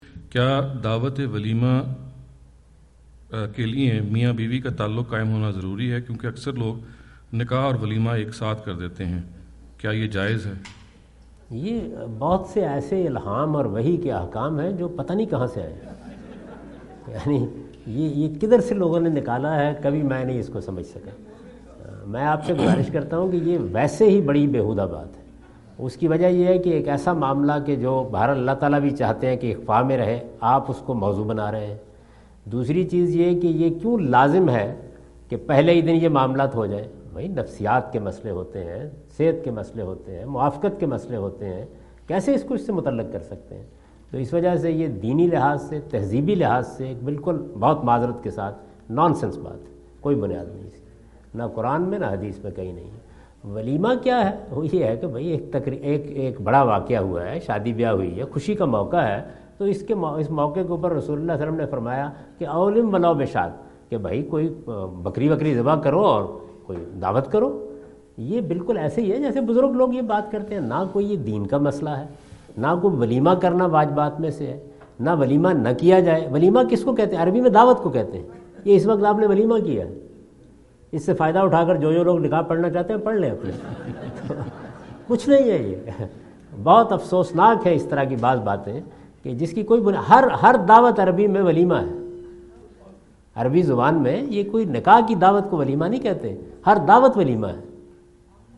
Category: Foreign Tours / USA 2017 / Questions_Answers /
Javed Ahmad Ghamidi answer the question about "Consummation of Marriage before Walima" During his US visit in Dallas on October 08,2017.